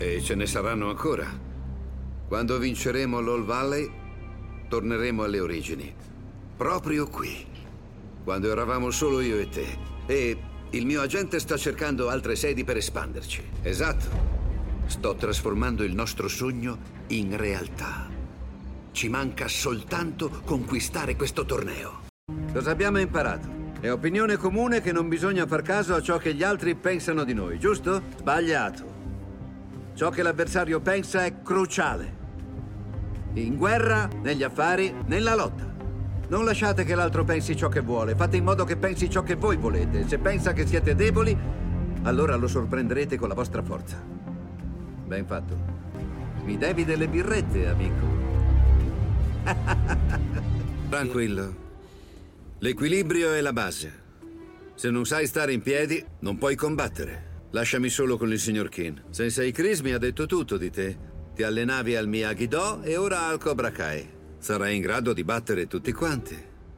telefilm "Cobra Kai", in cui doppia Thomas Ian Griffith.